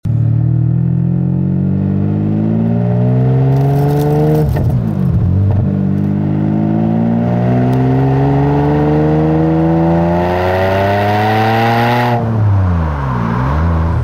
V12 5.5